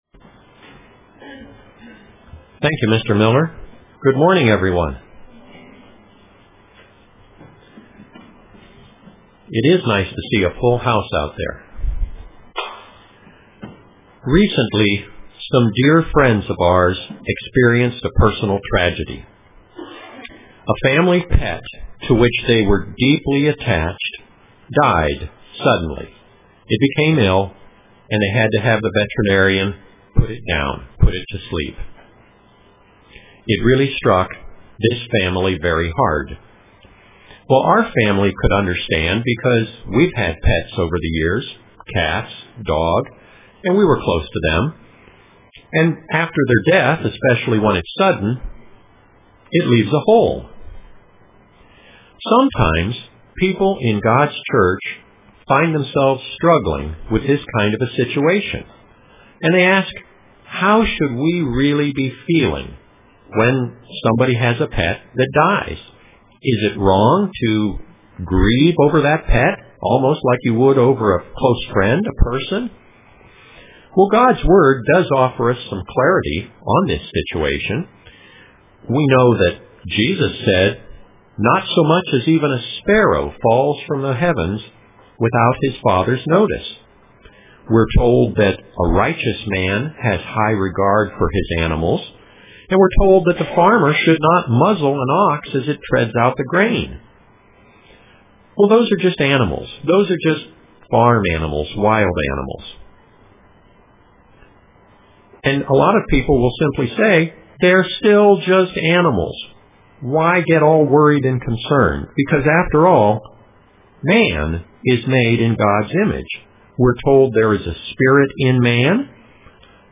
Print Grieving over the loss of Pets UCG Sermon Studying the bible?